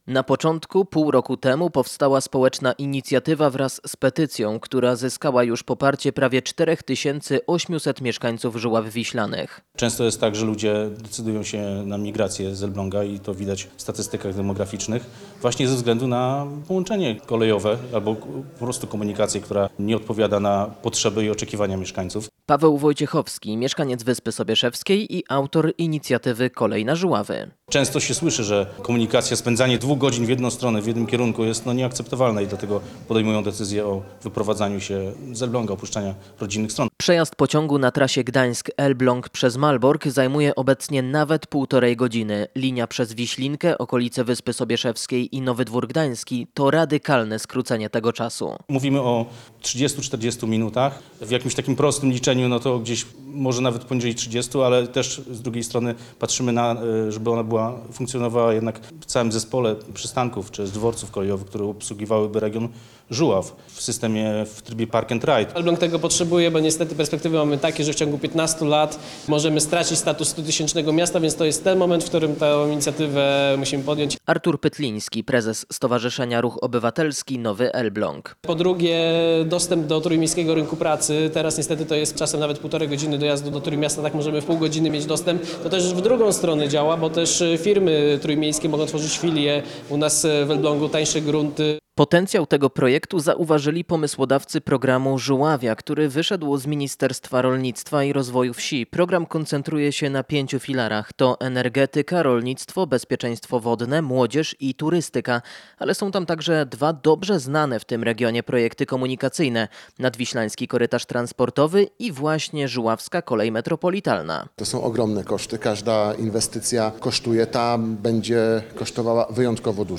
O szansach dla regionu rozmawiali w Nowym Dworze Gdańskim samorządowcy, parlamentarzyści i społecznicy.